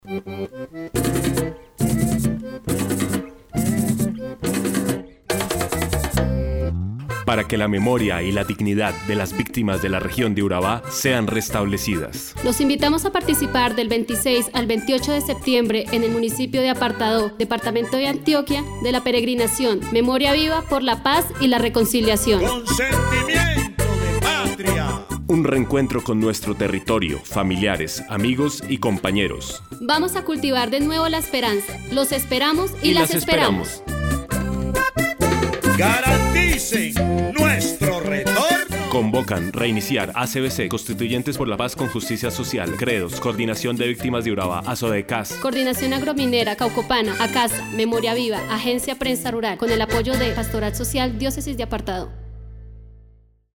Cuña Apartadó (MP3 – 2 MB)